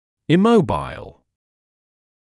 [ɪ’məubaɪl][и’моубайл]неподвижный, фиксированный